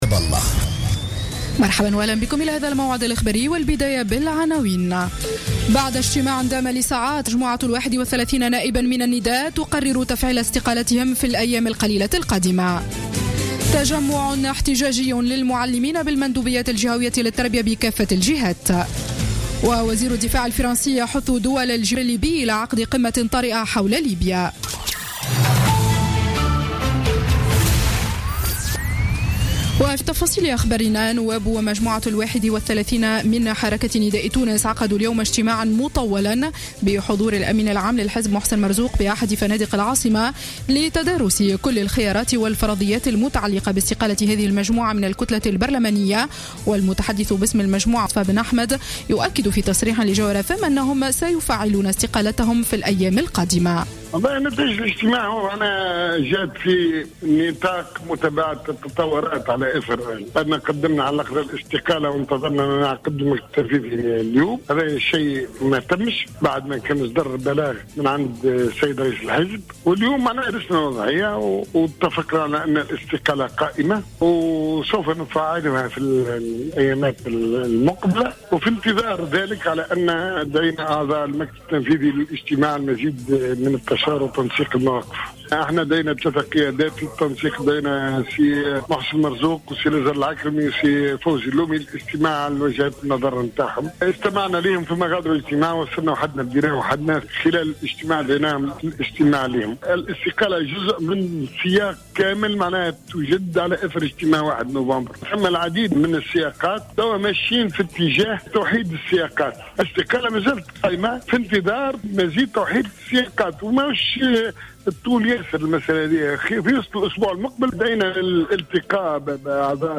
نشرة أخبار السابعة مساء ليوم الأحد 22 نوفمبر 2015